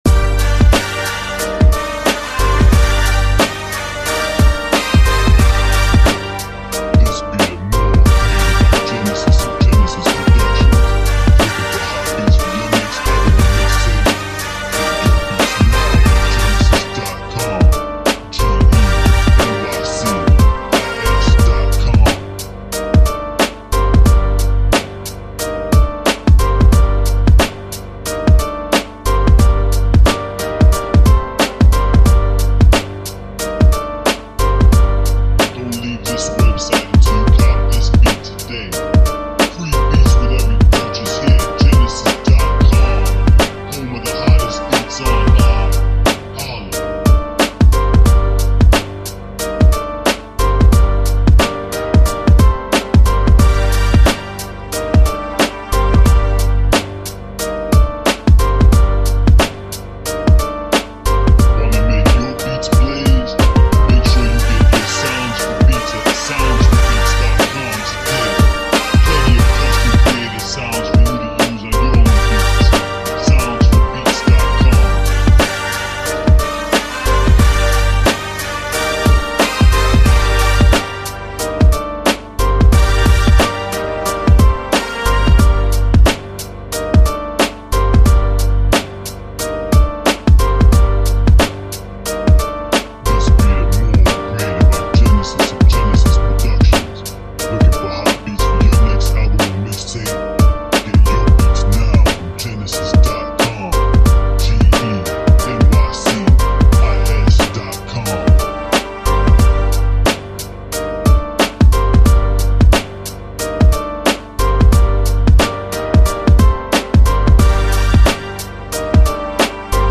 Dark Street East Coast Beat